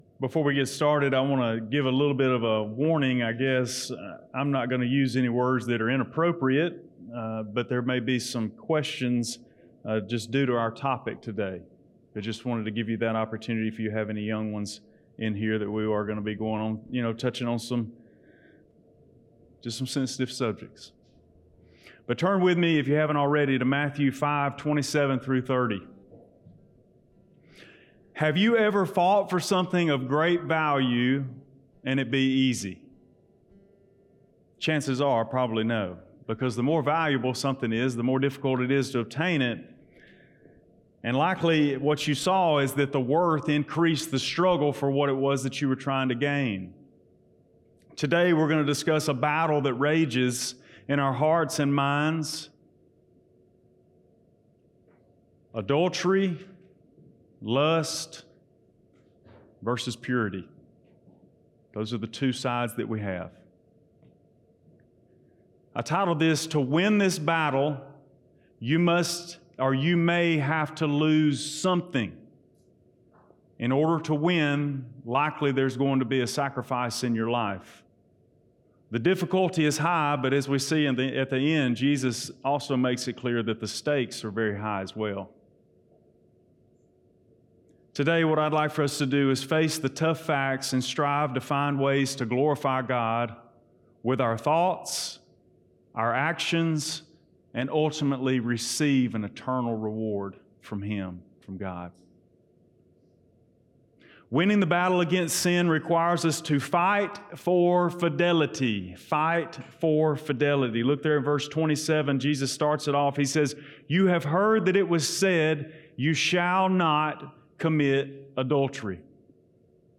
Blount Springs Baptist Church Sermons